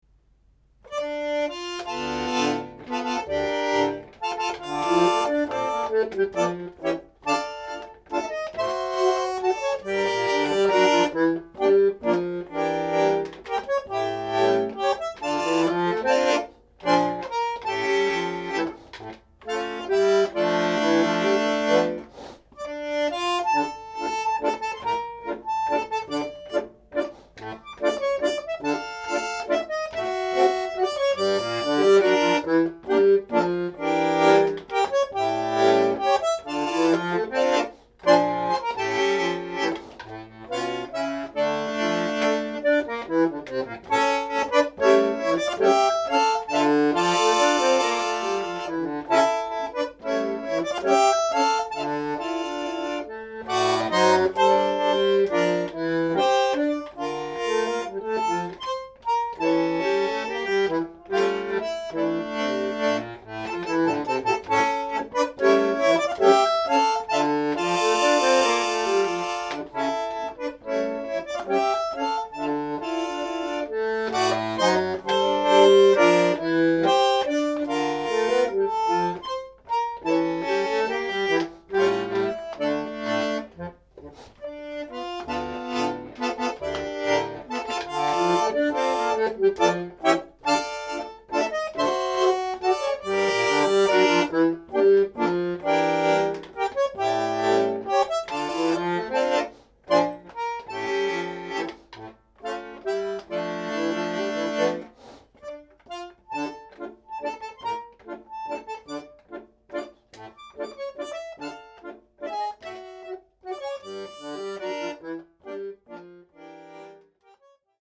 bandoneonBandoneon